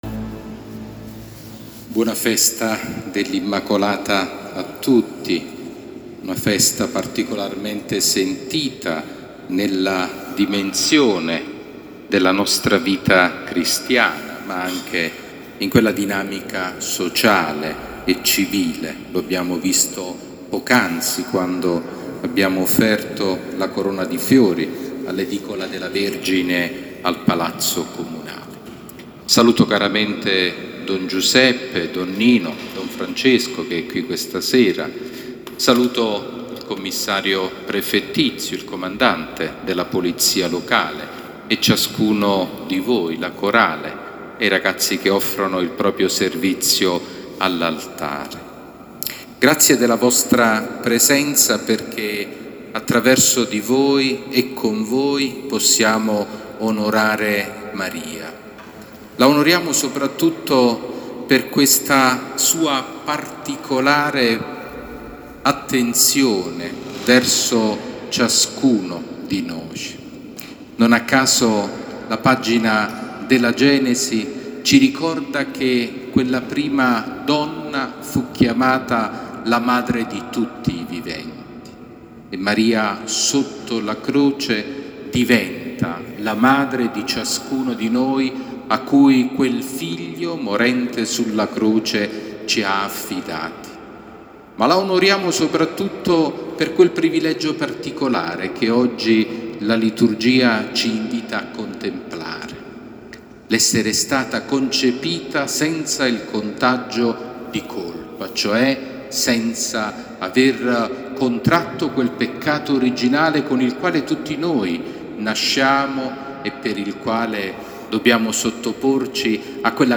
Omelia di Mons. Sabino Iannuzzi durante la Santa Messa nella Solennità dell’Immacolata
L'omelia del Vescovo Sabino durante la Santa Messa nella Solennità dell'Immacolata presso la Parrocchia San Lorenzo M. di Massafra.